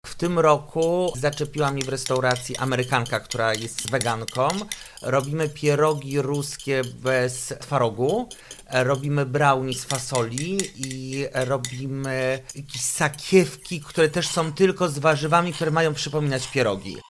mówi jeden z restauratorów